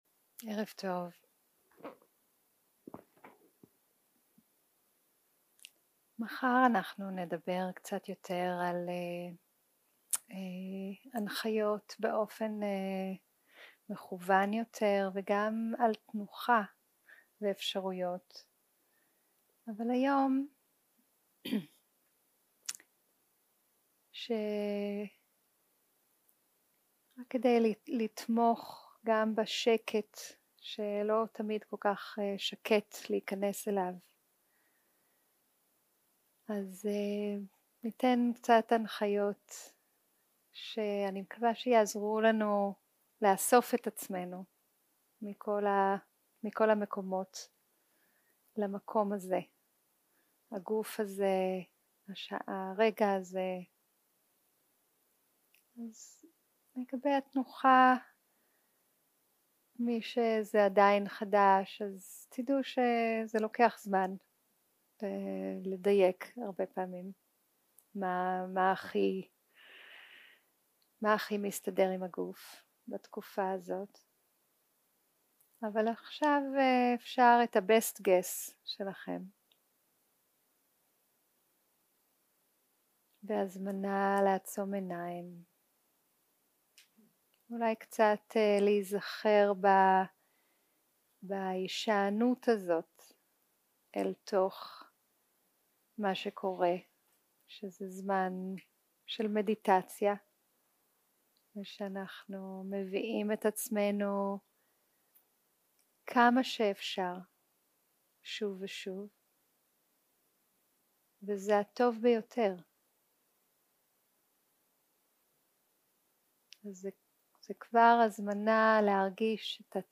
יום 1 - הקלטה 1 - ערב - מדיטציה מונחית - התקרקעות ותשומת לב לגוף
יום 1 - הקלטה 1 - ערב - מדיטציה מונחית - התקרקעות ותשומת לב לגוף 0:00 0:00 סוג ההקלטה: Dharma type: Guided meditation שפת ההקלטה: Dharma talk language: Hebrew